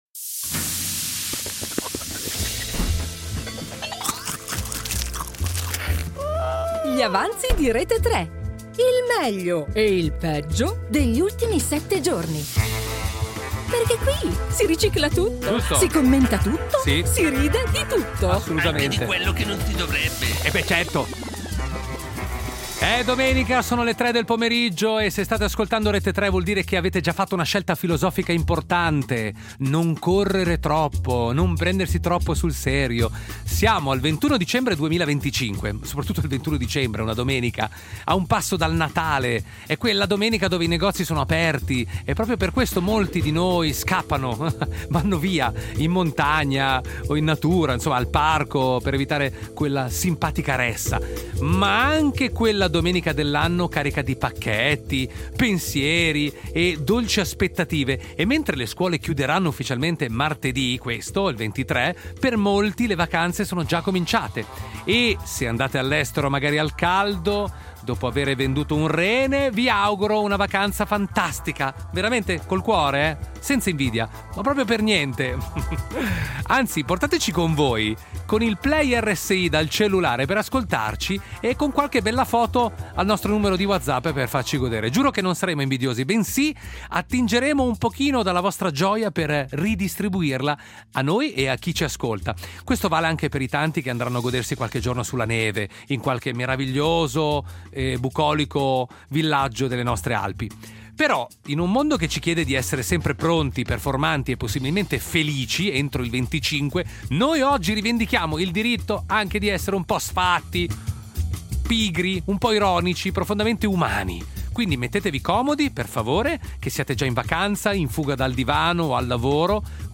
Due ore di musica, momenti memorabili (o dimenticabili), notizie che hanno fatto rumore e altre che hanno solo fatto vibrare il telefono. Un programma che non butta via niente: si ricicla tutto, si commenta tutto, si ride di tutto.